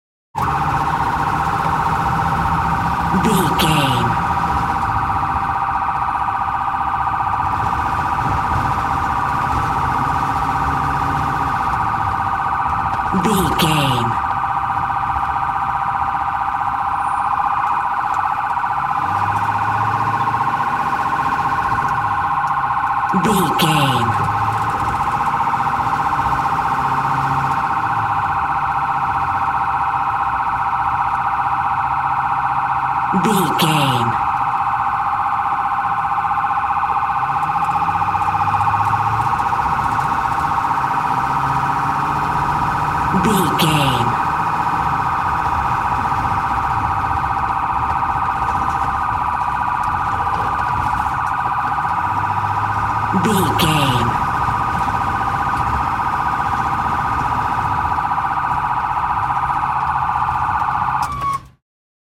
Ambulance Int Drive Engine Stress Siren
Sound Effects
urban
chaotic
dramatic